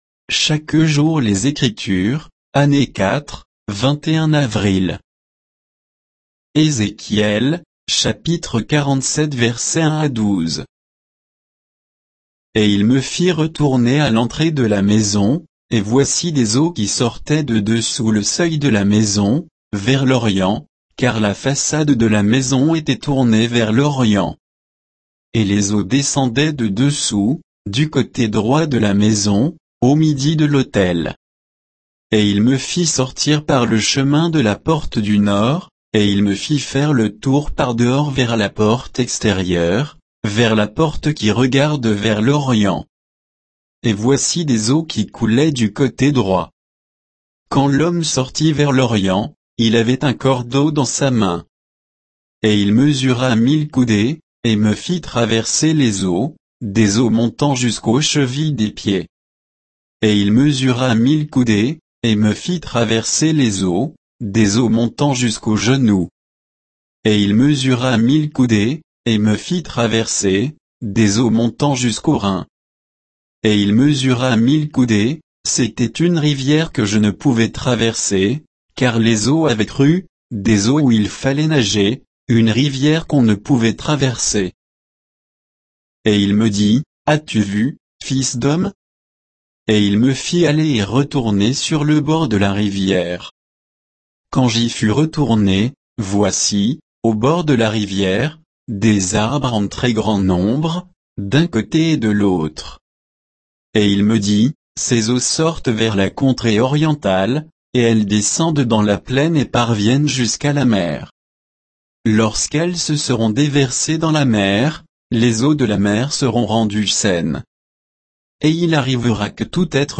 Méditation quoditienne de Chaque jour les Écritures sur Ézéchiel 47